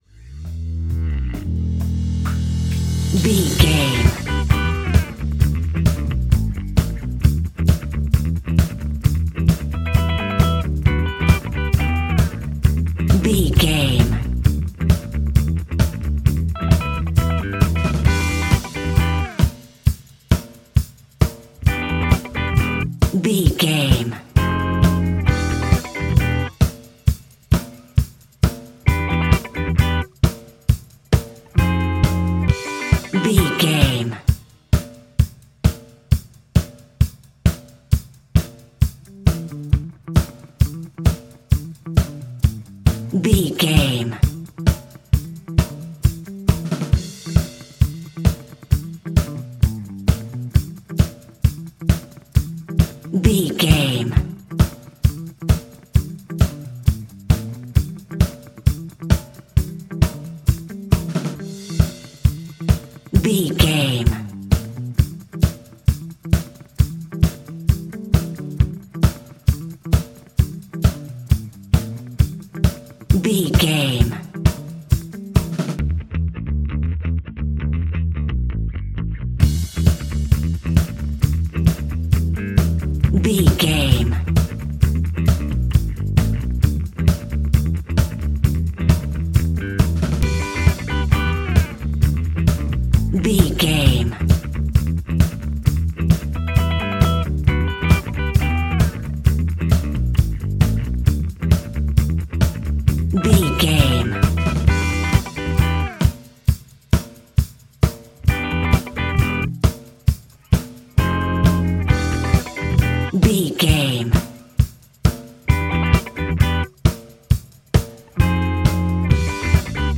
Ionian/Major
E♭
house
electro dance
synths
techno
trance
instrumentals